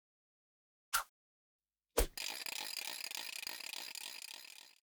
AA_remote_control.ogg